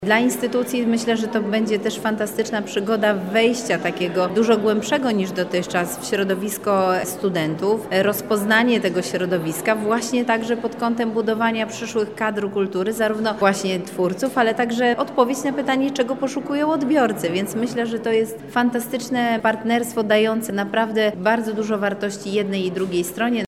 O szansach, które daje świeżo zawiązana współpraca mówi Zastępca Prezydenta ds. Kultury, Sportu i Partycypacji Beata Stepaniuk-Kuśmierzak: